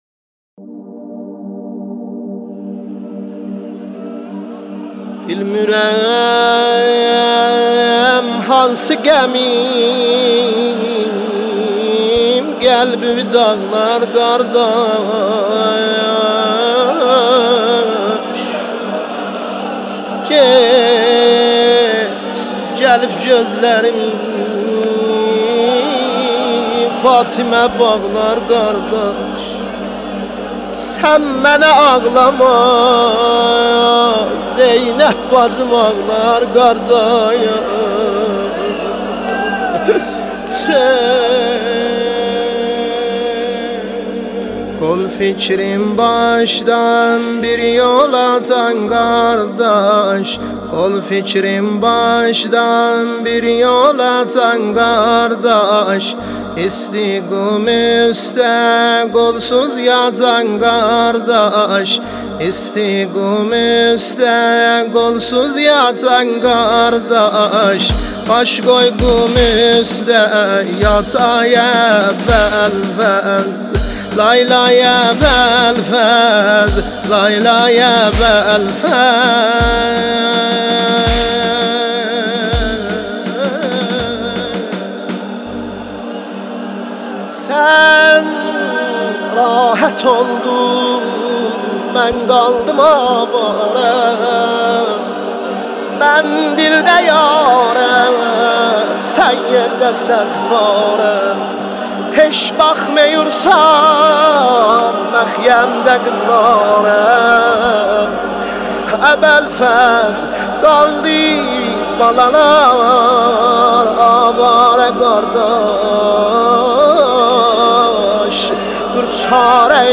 مداحی ترکی